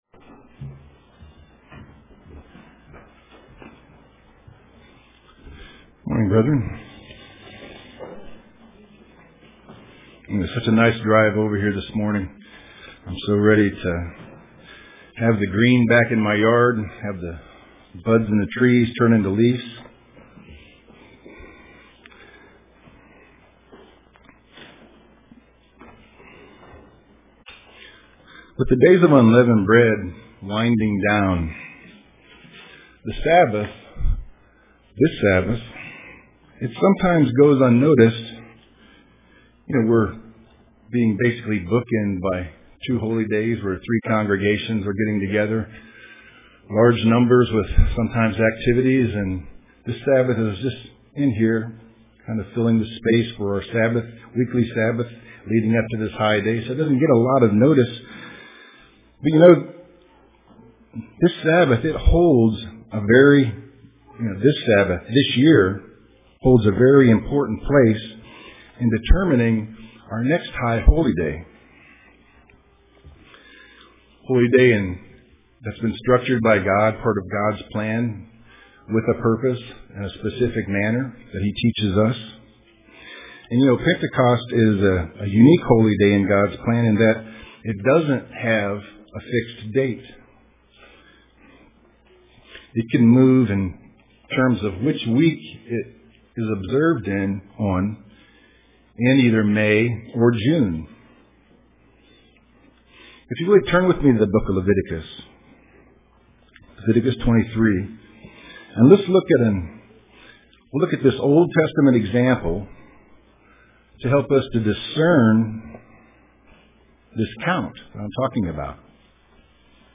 Print Counting 50 UCG Sermon Studying the bible?